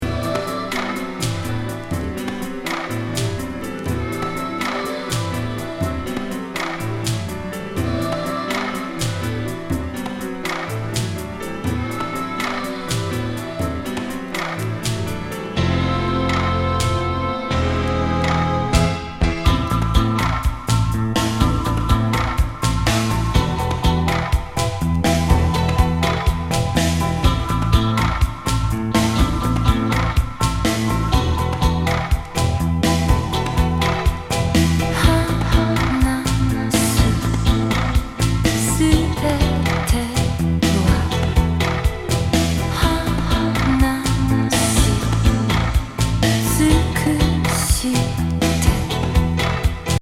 ヨーロピアン85年作。